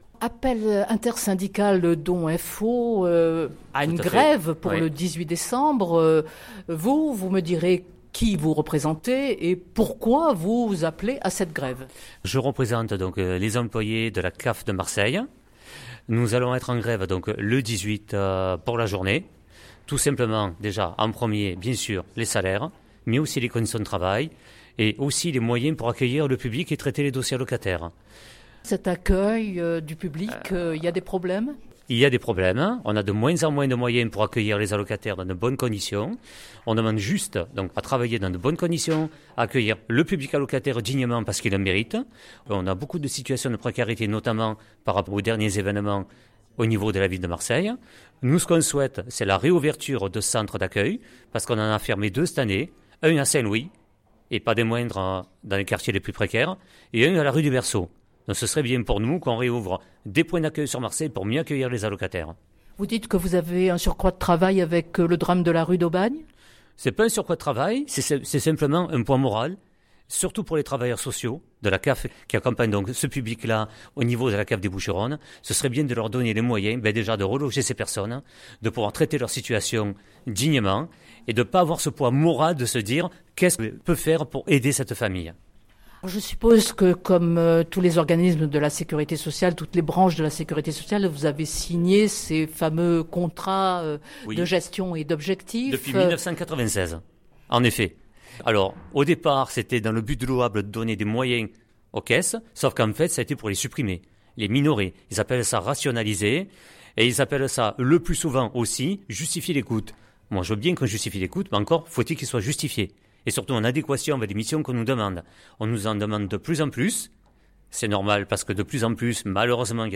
Entretien…